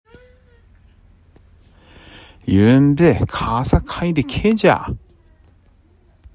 津軽方言：古代の発音
津軽方言のを聞くと、古代日本語の音声の一部が、現代の津軽方言に受け継がれていることが分かります。